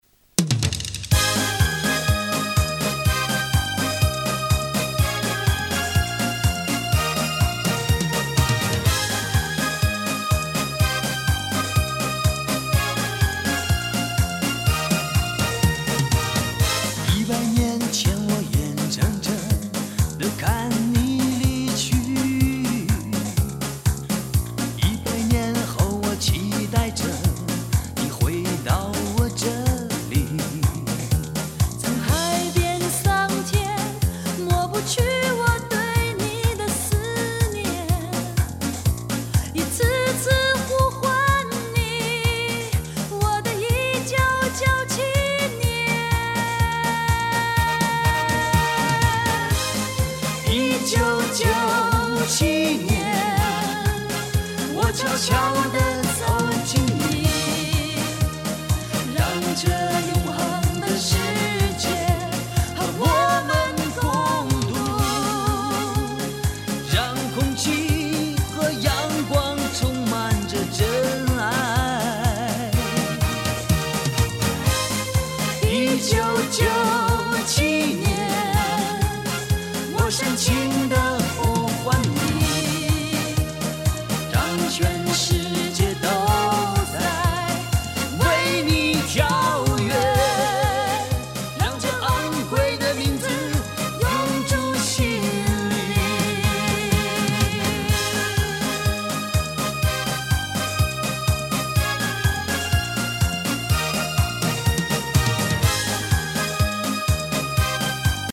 注：swf视频的清晰度和音轨质量与原文件差的太远（试看为1分钟，难得上传，swf也不清晰，所以没上传完整）
快四